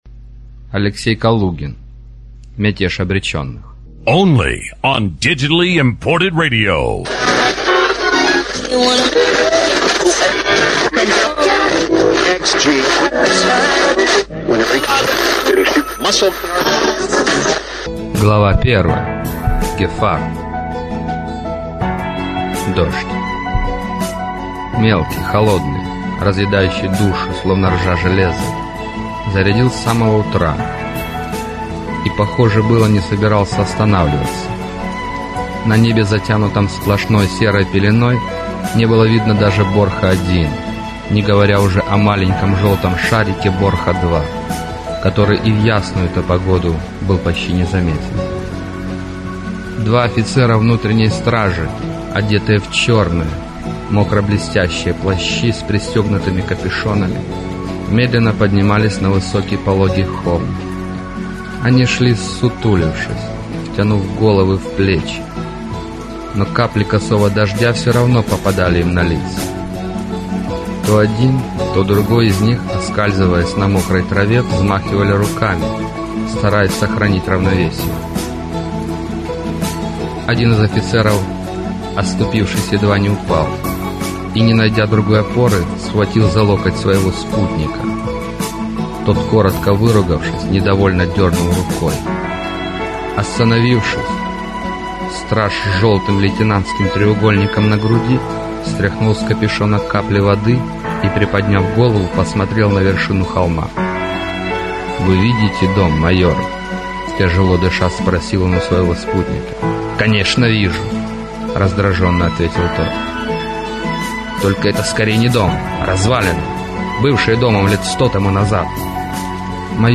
Аудиокнига Мятеж обреченных | Библиотека аудиокниг